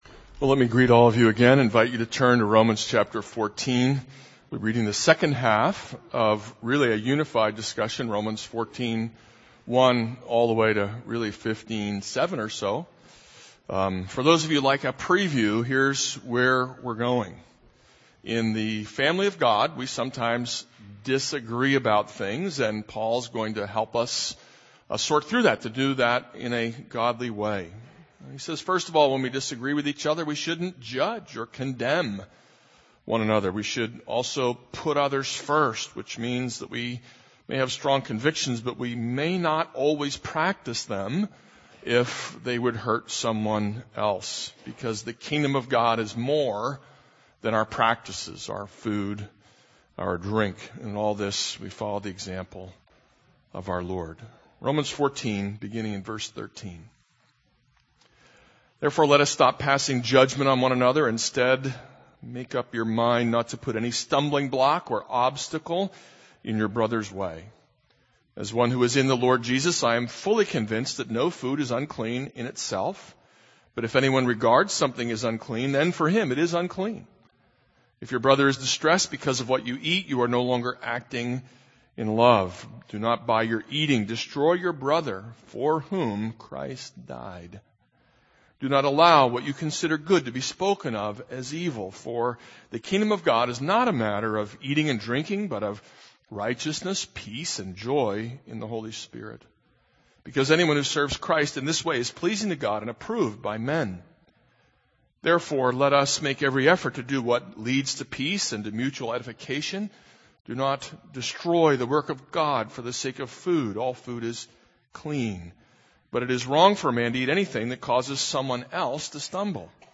This is a sermon on Romans 14:13-15:2.